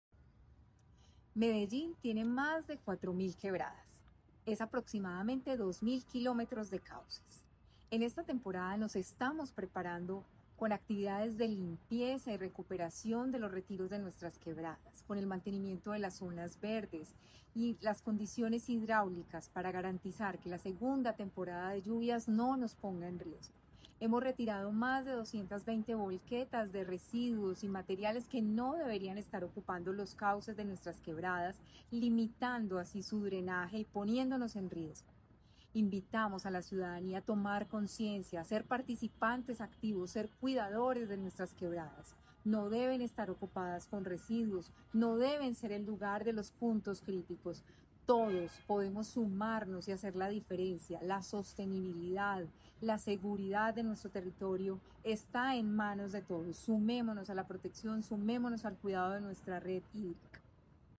Declaraciones-de-la-secretaria-de-Medio-Ambiente-Marcela-Ruiz-Saldarriaga.mp3